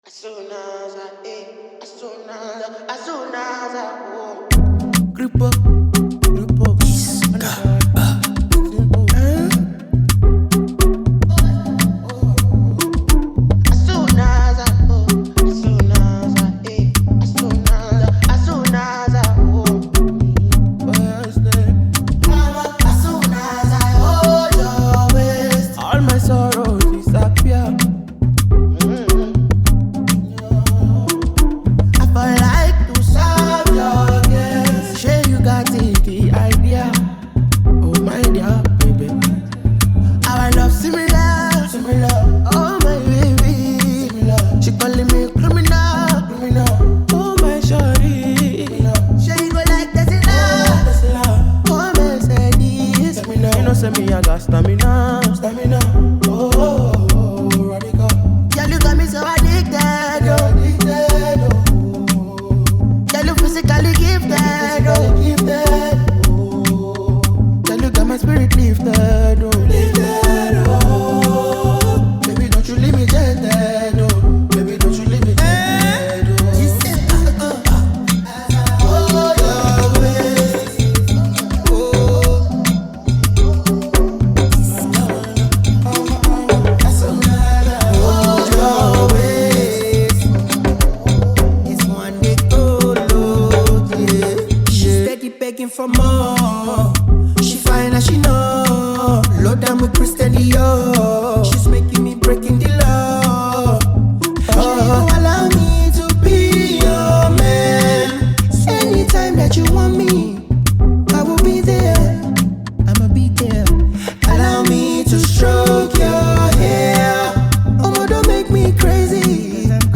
blends Afrobeat with contemporary sounds
With its high energy tempo and catchy sounds